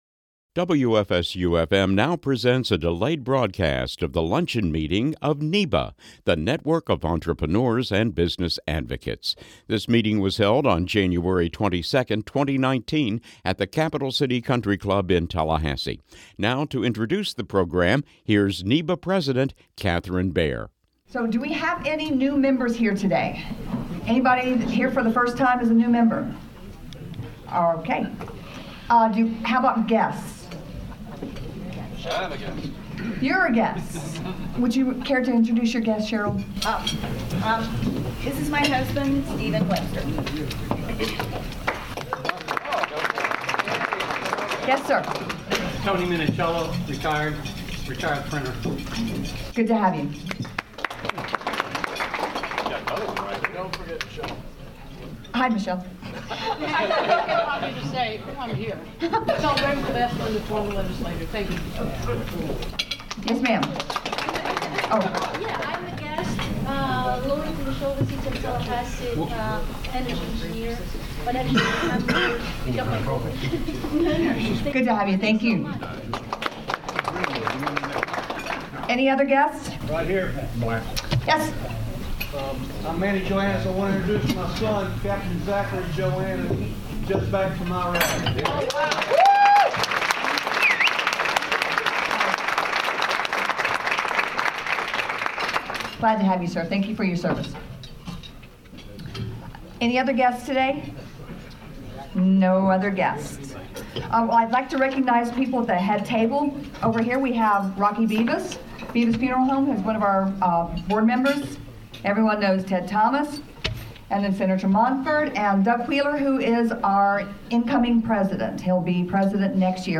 Florida Second District Congressman Neal Dunn was originally slated to appear at this NEBA luncheon, but was suddenly called back to Washington. So Florida's Third District State Senator Bill Montford came in to discuss the region's response to Hurricane Michael and hot topics likely to be addressed during this year's state legislative session.